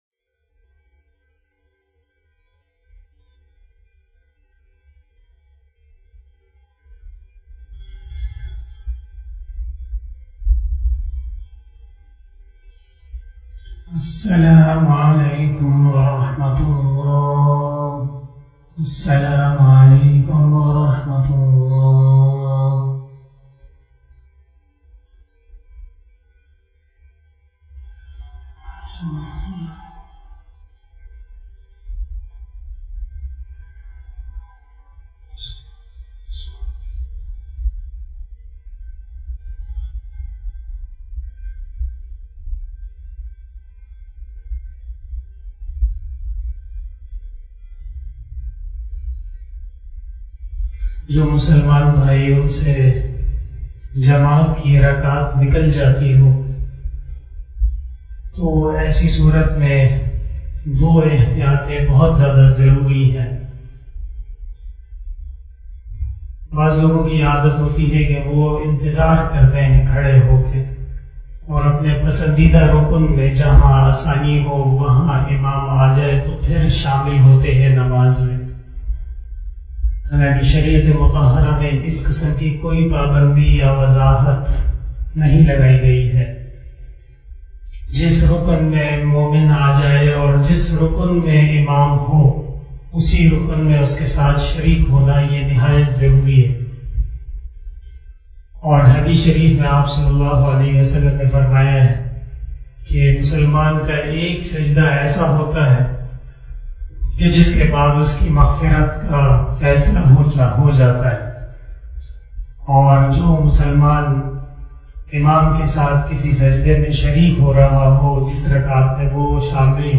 033 After Asar Namaz Bayan 13 July 2021 (03 Zilhajjah 1442HJ) Wednesday